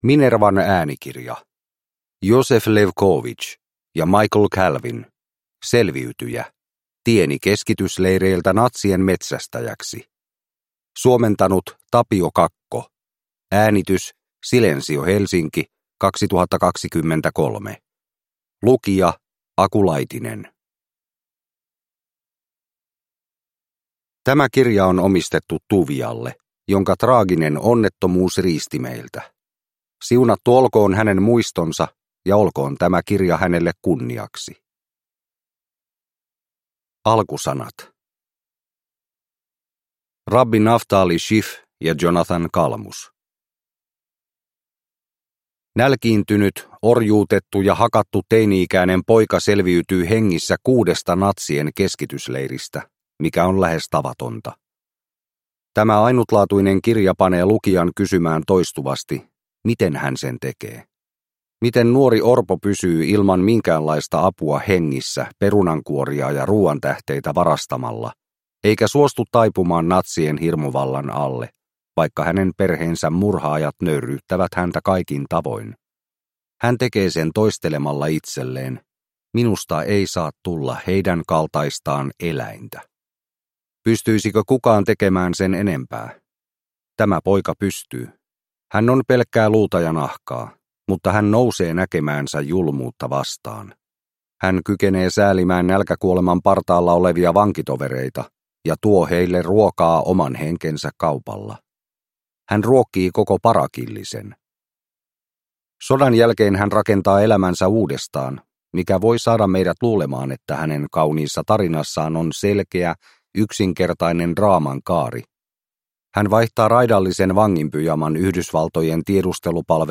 Selviytyjä – Tieni keskitysleireiltä natsien metsästäjäksi – Ljudbok – Laddas ner